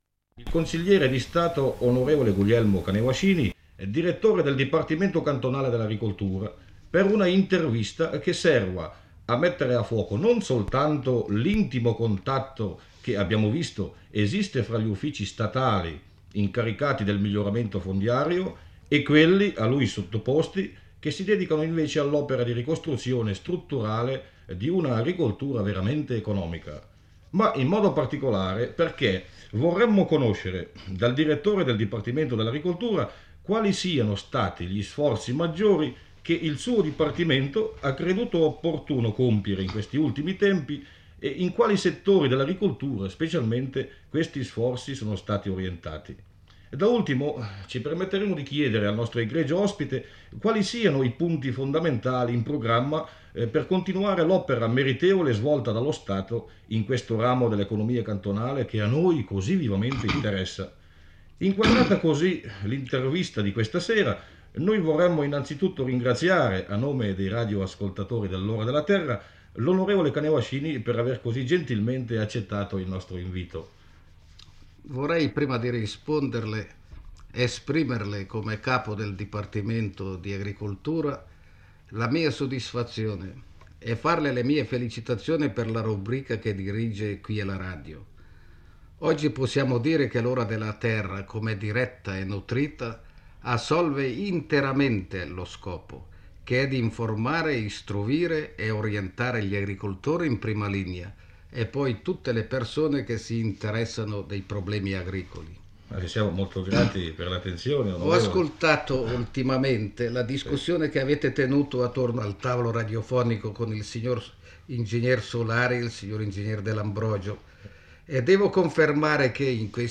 incontra il Consigliere di stato Guglielmo Canevascini, direttore del Dipartimento cantonale dell'agricoltura, per parlare delle politiche agricole messe in atto in questi anni dalle autorità cantonali.